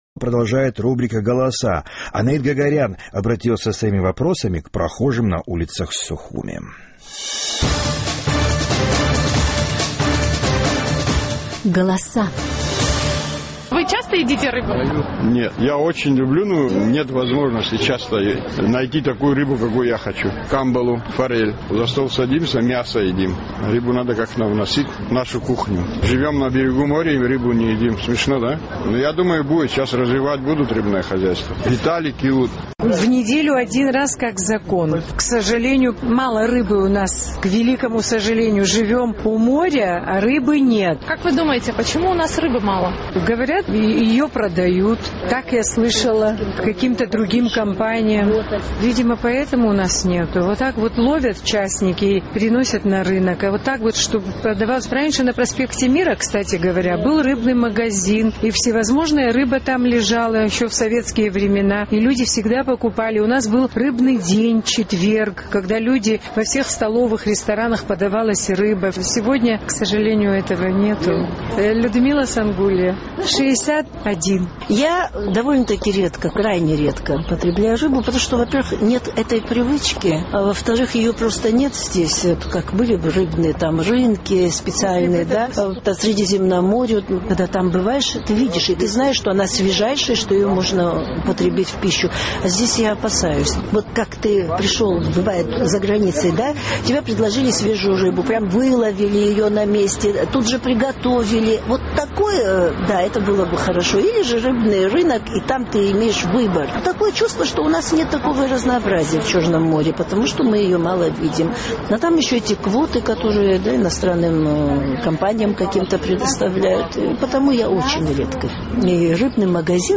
Наш корреспондент поинтересовался у жителей Сухума, почему Абхазия испытывает дефицит рыбы. Некоторые считают, что это связано с глобальными экологическими угрозами и отловом рыбы.